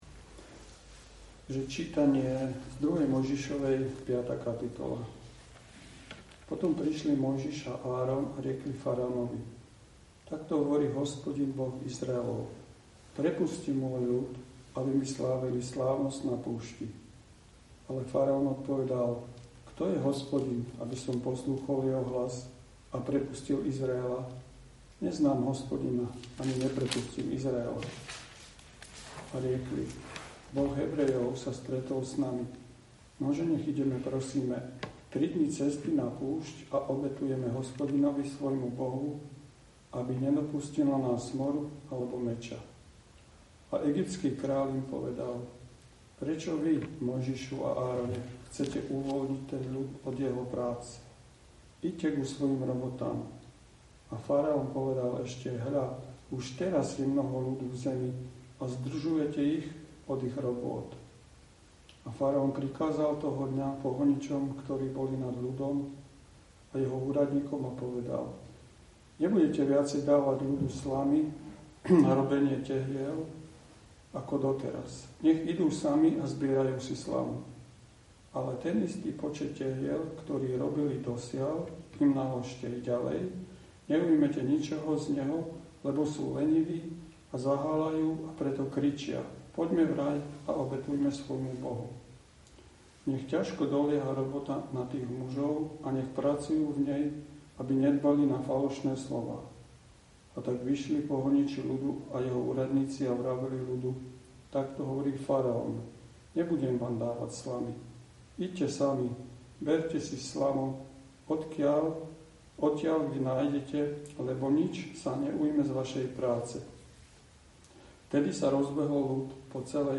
Kázeň postupne prechádza cez nasledovné témy: